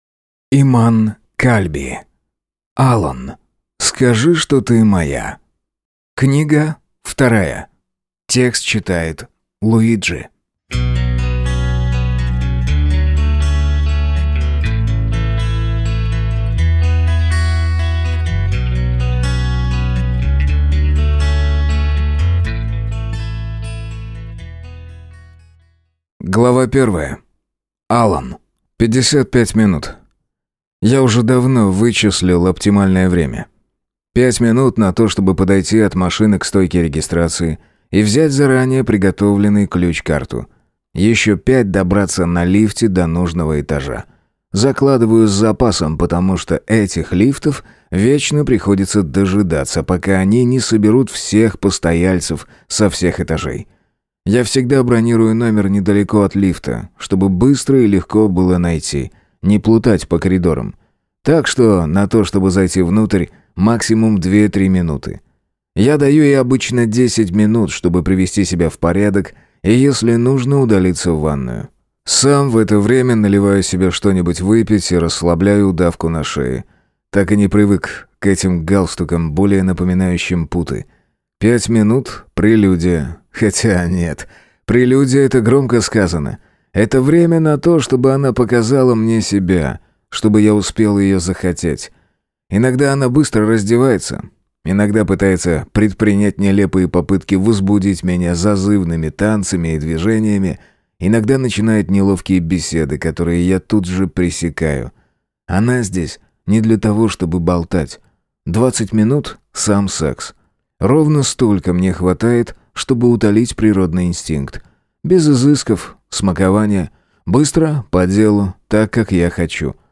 Аудиокнига Алан. Скажи, что ты моя 2 | Библиотека аудиокниг